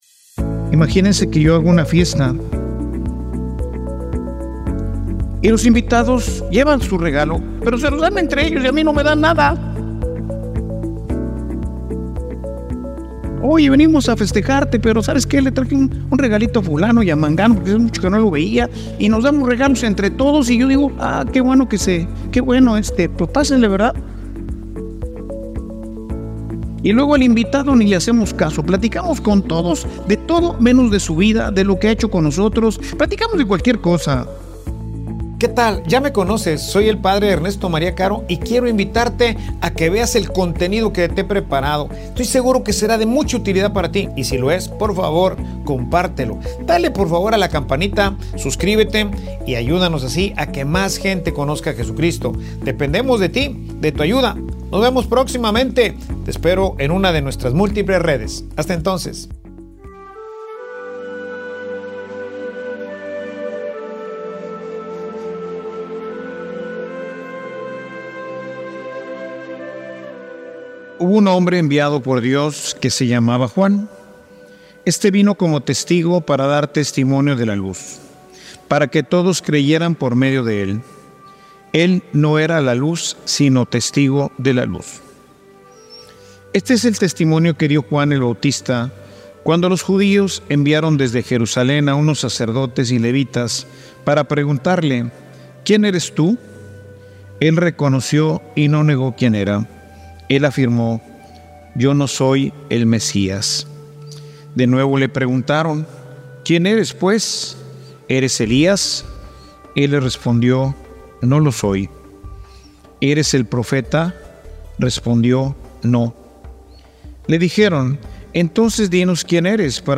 Homilia_Una_fiesta_que_ya_no_celebra_a_Cristo.mp3